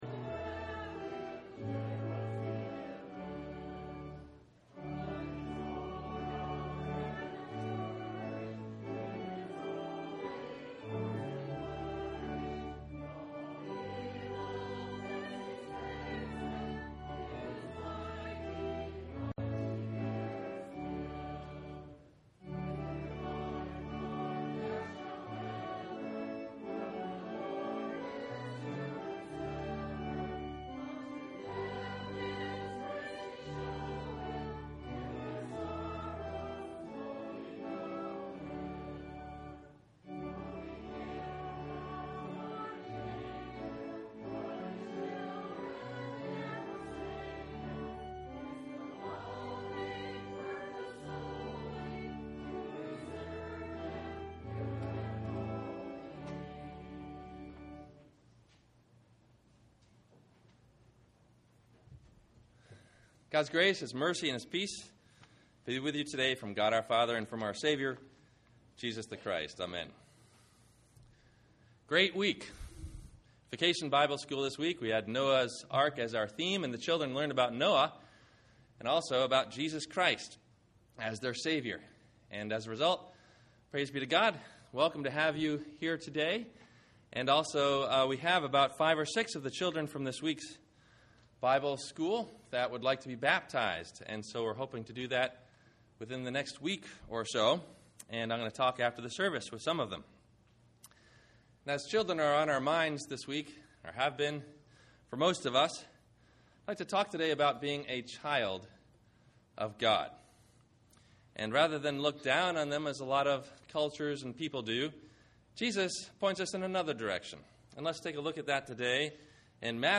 Are You A Child Of God ? – Sermon – July 18 2010